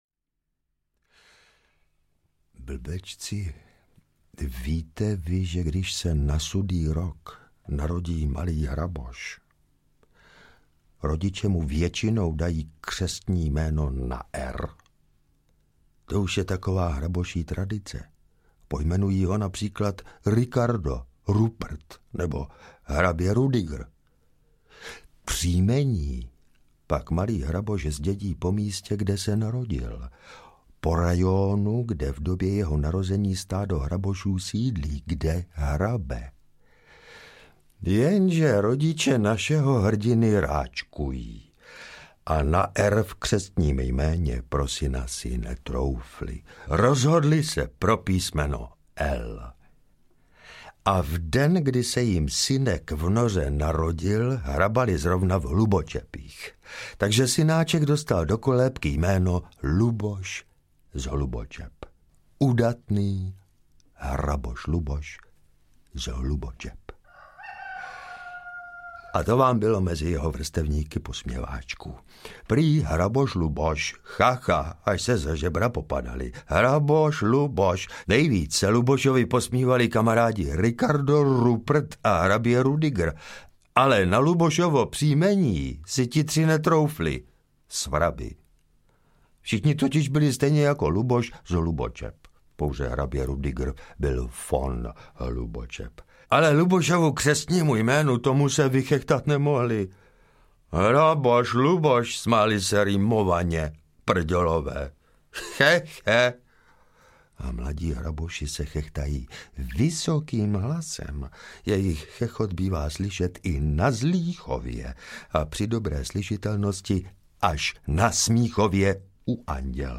The Vole - Hraboš hrdina audiokniha
Ukázka z knihy